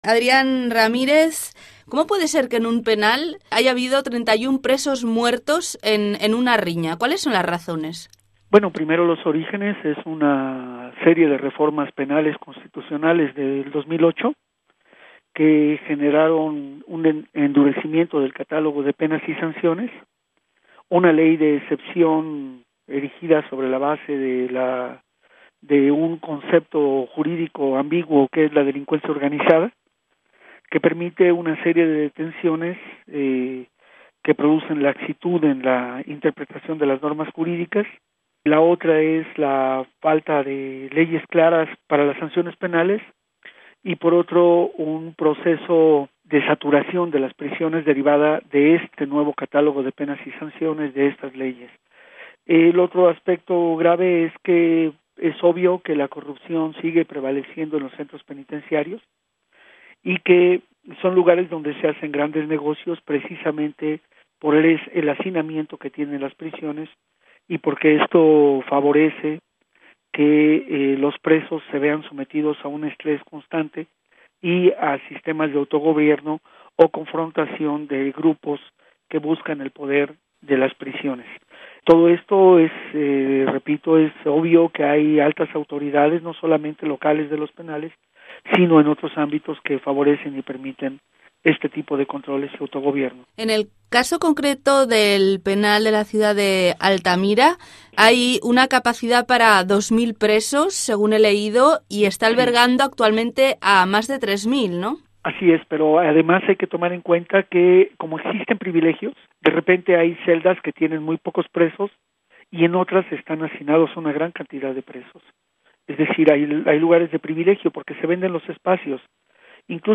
questa intervista  rilasciata a  Radio Francia Internacional,  ha denunciato  gravi responsabilità delle autorità penitenziarie rispetto alla sicurezza dei detenuti. Afferma che quanto accaduto é da mettere in relazione alle riforme penali costituzionali del 2008, alle leggi “di emergenza” che riempiono le carceri, alla corruzione e al fatto che si sta “privatizzando” l’amministrazione penitenziaria, fenomeno che aggiunto alla corruzione imperante nelle carceri costringe le famiglie a notevoli costi per permettere un livello di vita decente al proprio familiare detenuto.